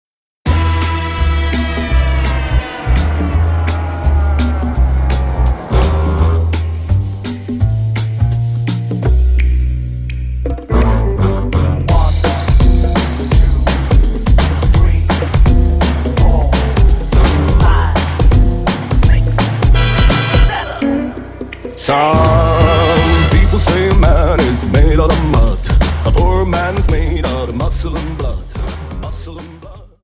Dance: Jive 42